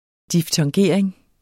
Udtale [ diftʌŋˈgeɐ̯ˀeŋ ]